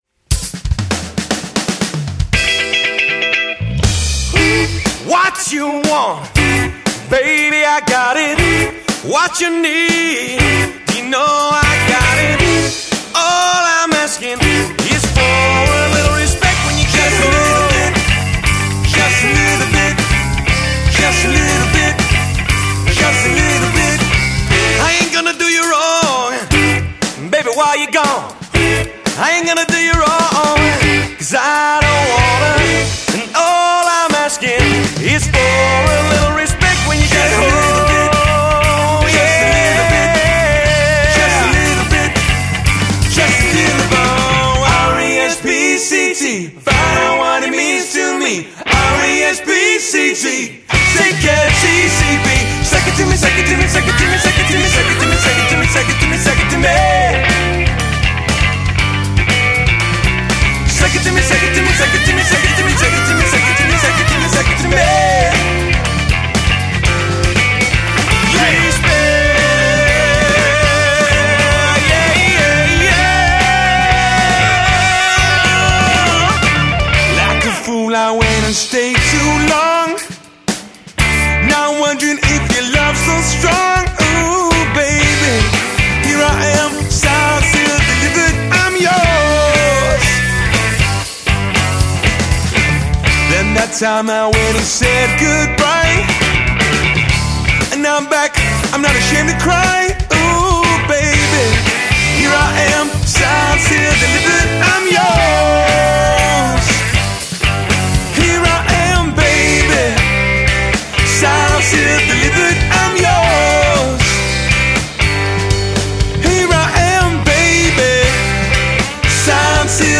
• Three-part harmonies & unique arrangements